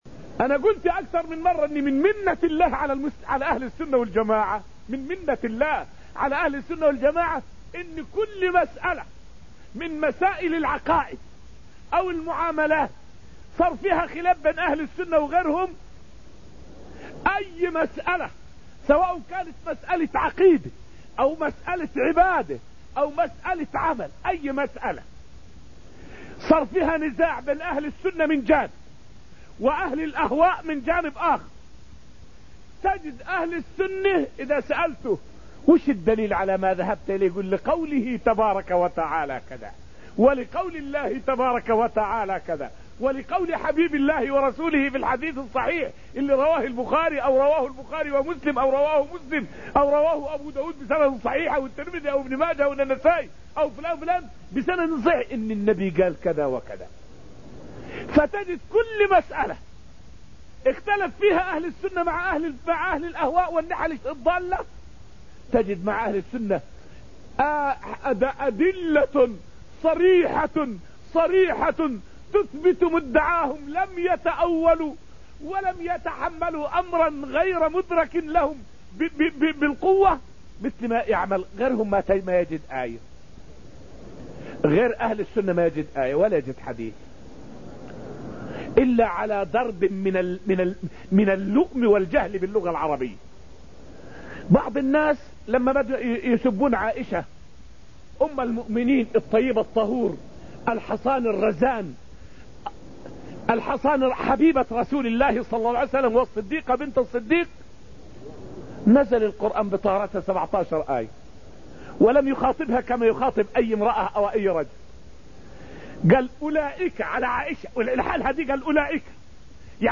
فائدة من الدرس الثامن عشر من دروس تفسير سورة الحديد والتي ألقيت في المسجد النبوي الشريف حول الفرق بين أهل السنة وأهل الأهواء في المرجعية.